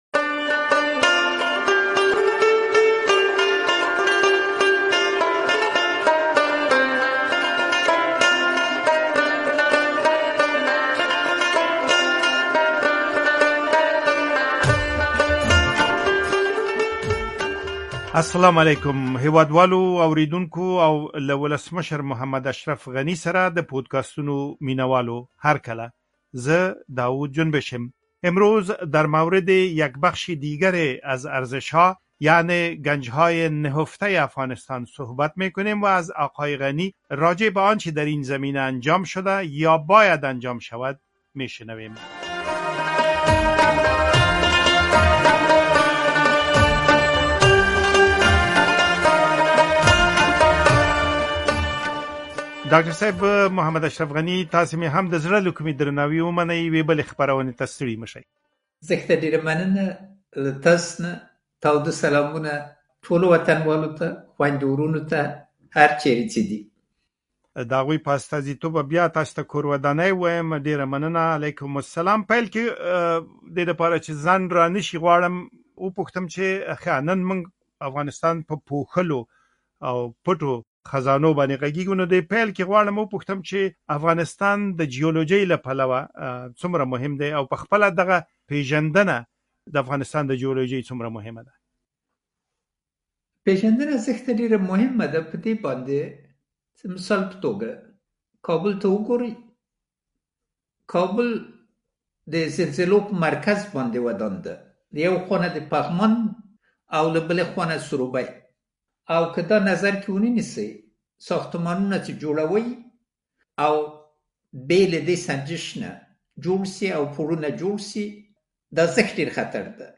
این بخش به بررسی منابع عظیم زیرزمینی افغانستان از جمله معادن، نفت و گاز پرداخته و کشف تاریخی، اهمیت زمین‌شناسی و پتانسیل اقتصادی آن‌ها را مورد تحلیل قرار می‌دهد. در این گفتگو بر اهمیت سروی‌های علمی زمین‌شناسی، قراردادهای شفاف و تخصص ملی قوی در مدیریت مسئولانه این منابع تأکید شده است.